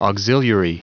Prononciation du mot auxiliary en anglais (fichier audio)
Prononciation du mot : auxiliary